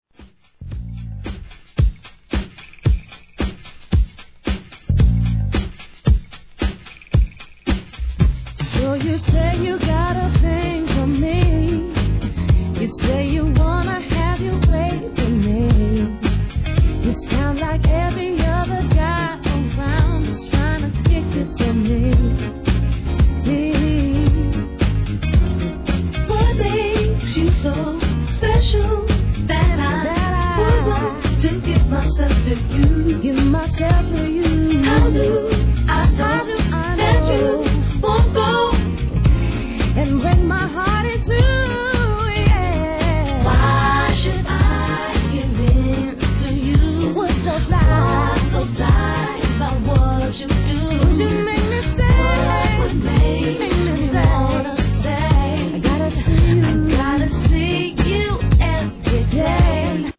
1. HIP HOP/R&B
ソウル・エッセンシャルな6曲を収録のR&Bコンピ!!